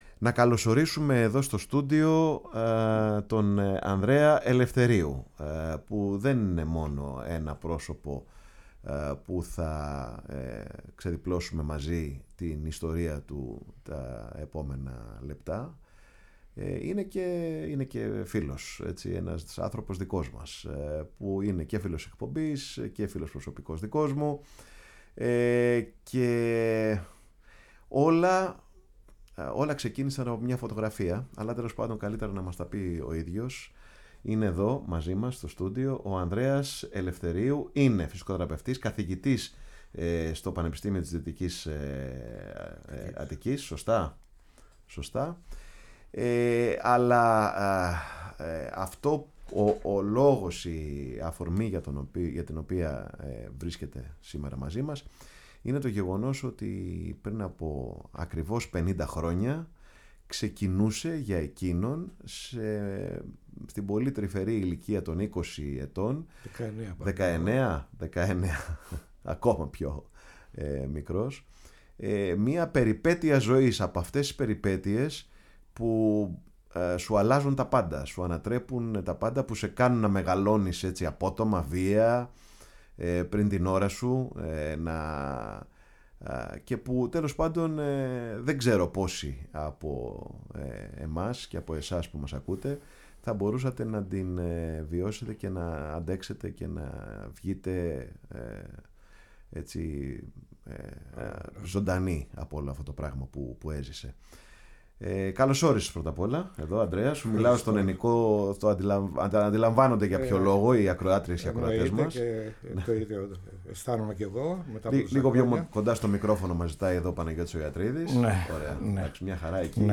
Καλεσμένος στο στούντιο της Φωνής της Ελλάδας , στην εκπομπή “Πάρε τον Χρόνο σου” , εξιστόρησε τα συγκλονιστικά γεγονότα που έζησε από πρώτο χέρι στη μάχη στο Συχαρί , όπου 180 Έλληνες της Κύπρου, στρατιώτες του Πυροβολικού, νέα παιδιά οι περισσότεροι, αντιμετώπισαν τους τούρκους καταδρομείς και οι μόλις 25 από αυτούς που επέζησαν μεταφέρθηκαν στις Τουρκικές φυλακές, όπου έζησαν το δικό τους “Εξπρές του Μεσονυχτίου”, για πάνω από τρεις μήνες.